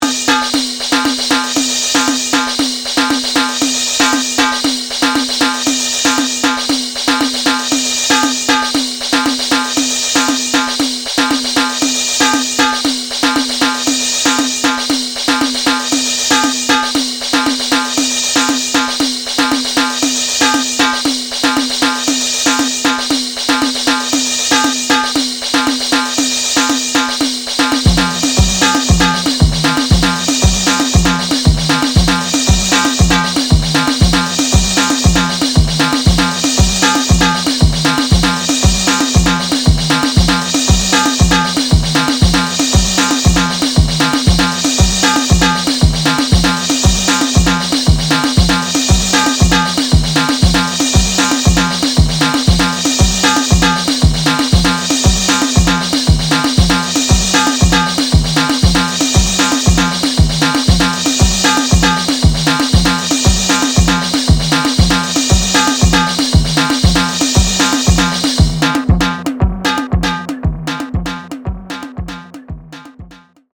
またもやダークな渦に飲み込まれます。